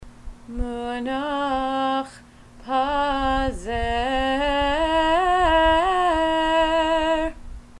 Cantillation for Megillat Esther